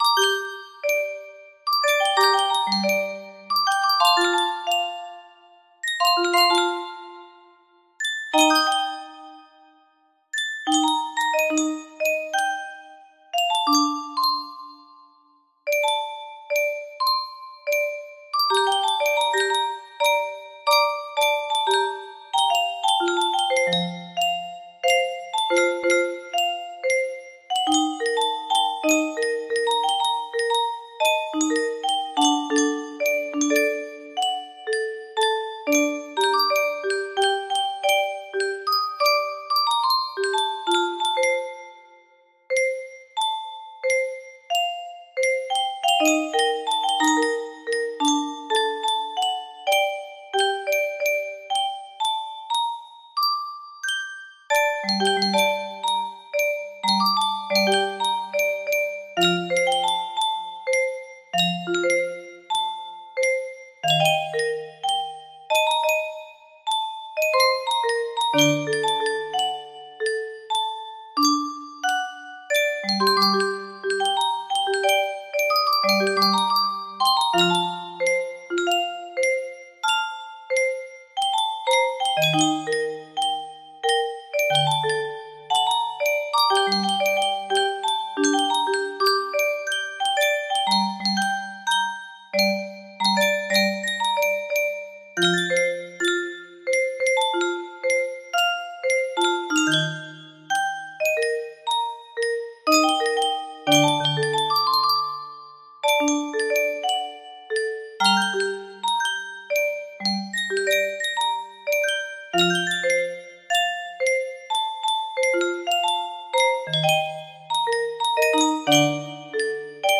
Whispers Of Dawn music box melody
Full range 60